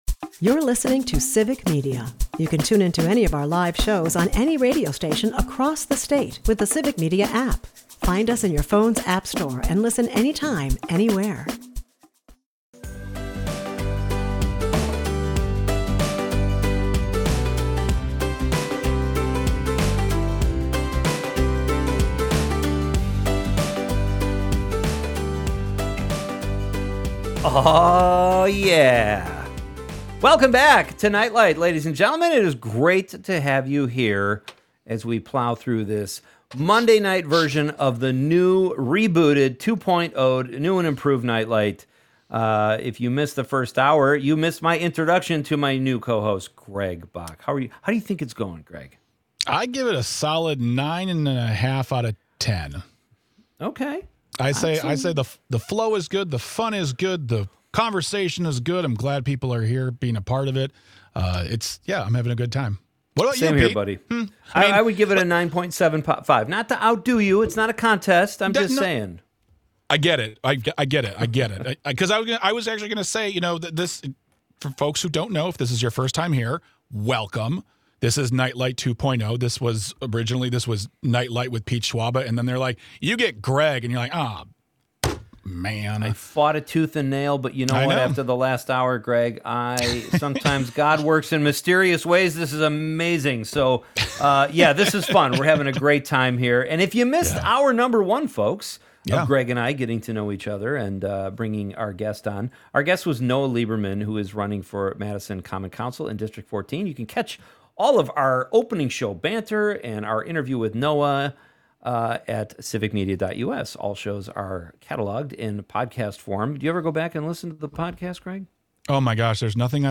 It’s the kind of back-and-forth that starts with one point and ends somewhere you definitely didn’t expect.
It’s movies, mayhem, and mildly concerning caffeine habits—all delivered with enough sarcasm to keep things interesting.